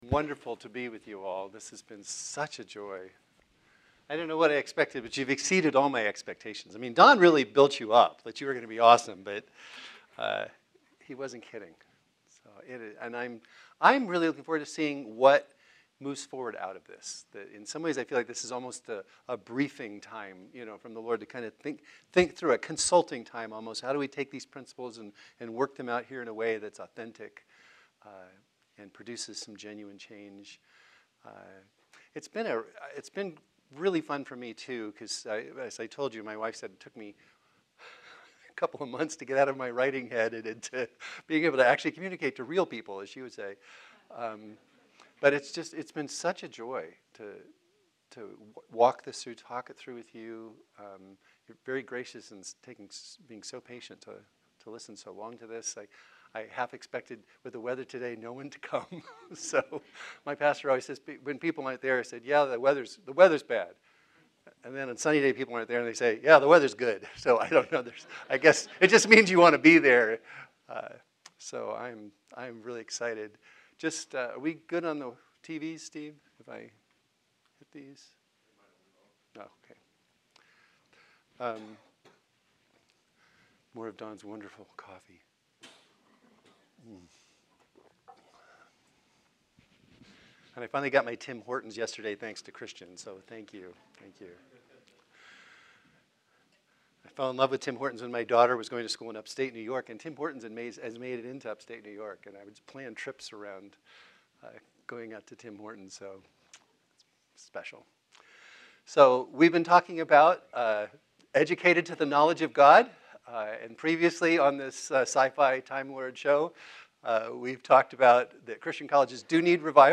Series: Dr. Garry Owens M.D. Memorial Academic Lectures | Educated to the Knowledge of Christ